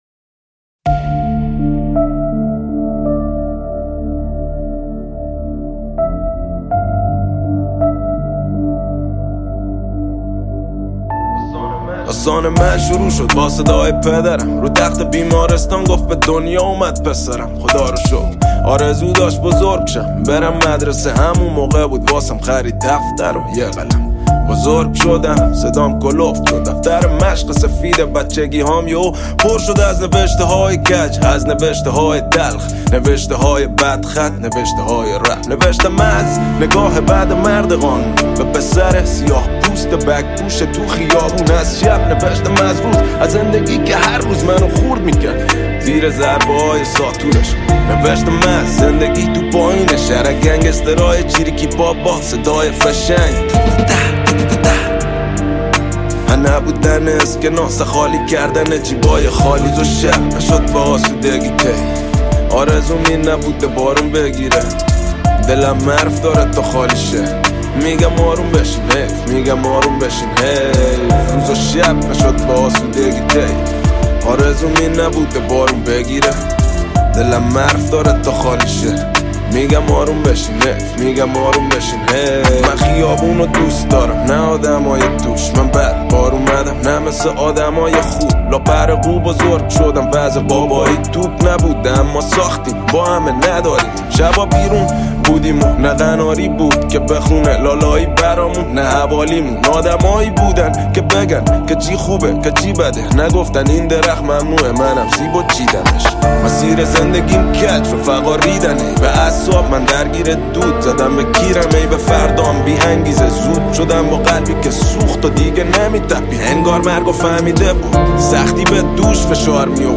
تک اهنگ ایرانی
موزیک غمگین